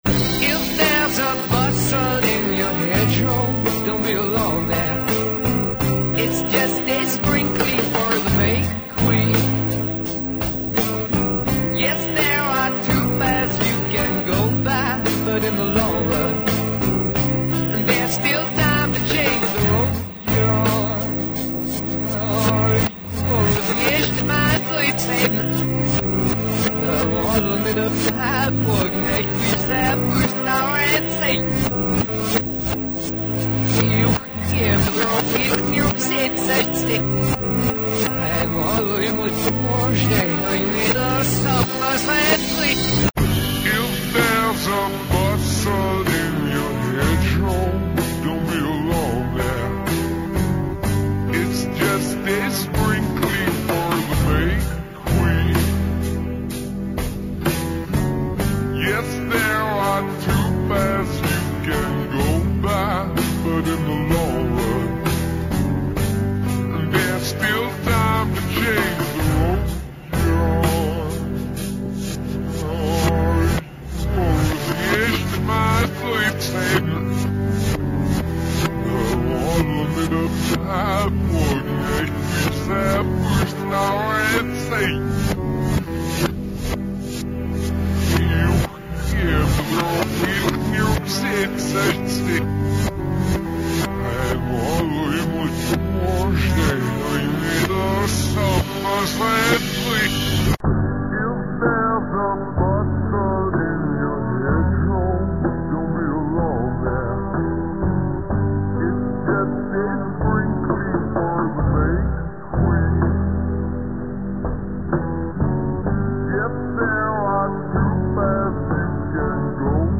This sample is played first forward, then backward.